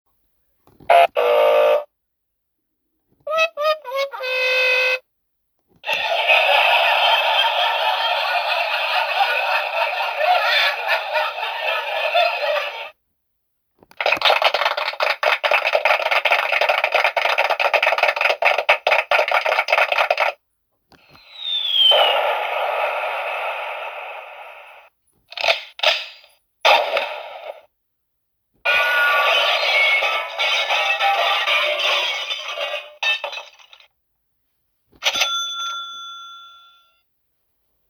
Our portable baby sound machine! has the highest volume on the market for a mini sound machine, allowing you to have a suitable atmosphere for baby, kids, adults and seniors.
Functions include: 30 non-looping soothing sleep sounds, 32 volume levels, 4 timer settings (30, 60, 90 and continuous), memory function, rechargeable battery and hanging loop.